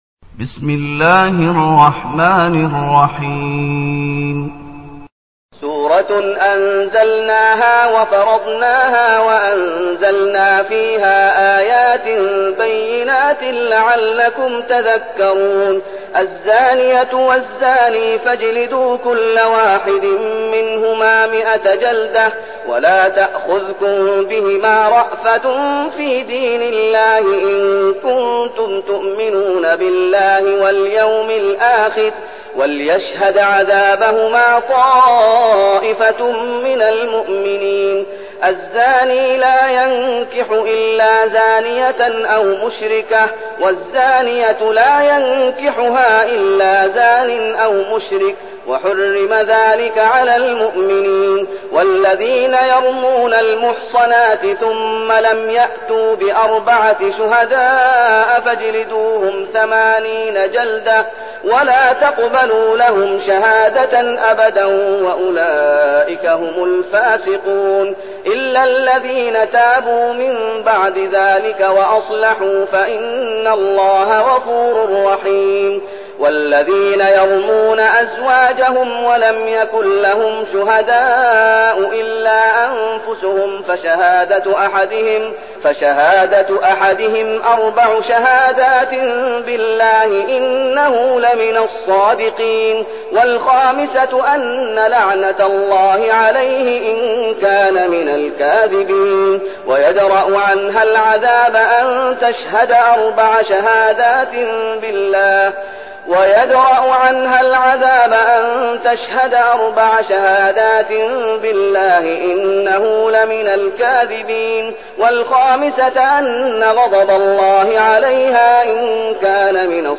المكان: المسجد النبوي الشيخ: فضيلة الشيخ محمد أيوب فضيلة الشيخ محمد أيوب النور